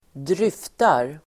Ladda ner uttalet
Uttal: [²dr'yf:tar]
dryftar.mp3